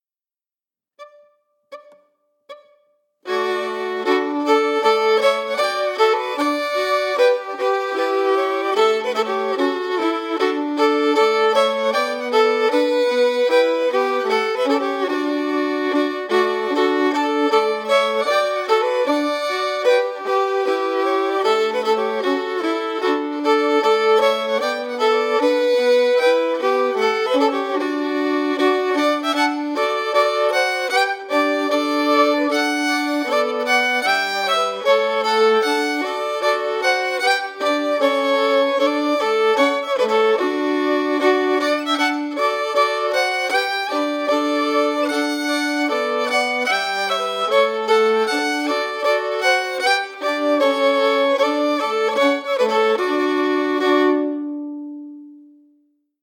Key: D
Form:March
Region:Scotland